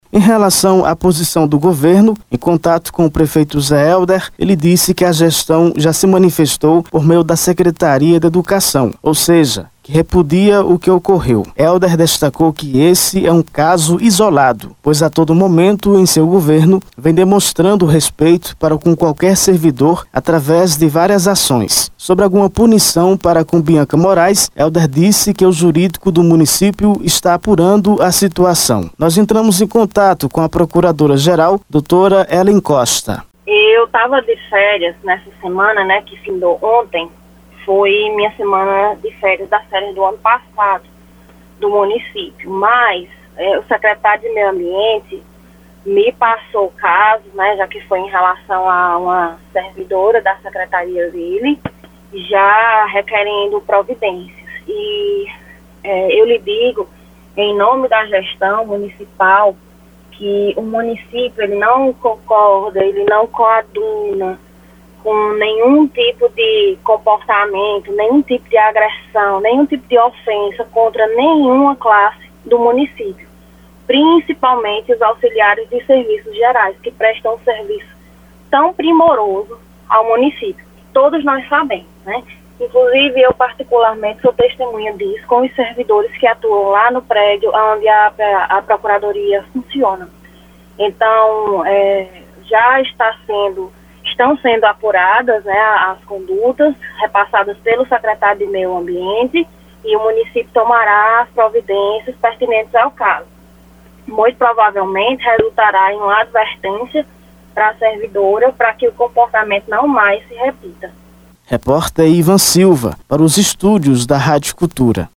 Ouça parte da reportagem que foi exibida no Grande Jornal da FM Cultura, na tarde deste sábado, 10: